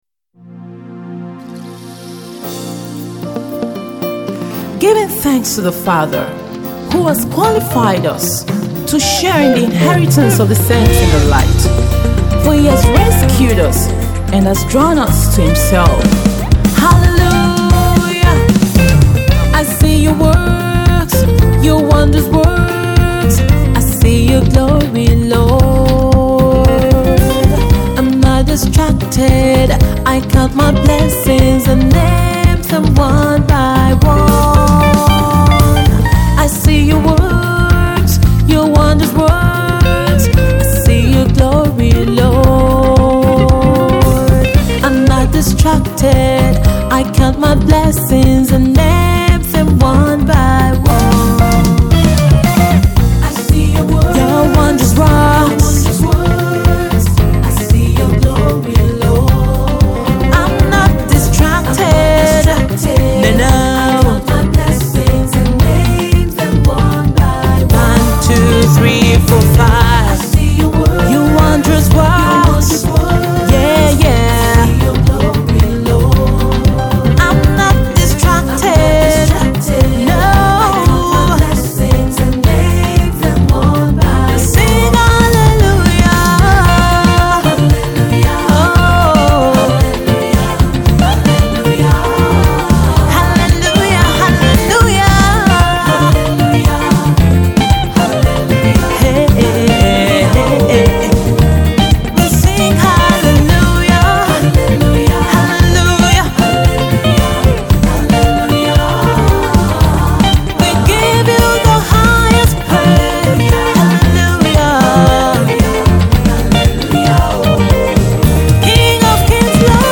Nigerian Gospel singer
timeless, sempiternal cheerful tune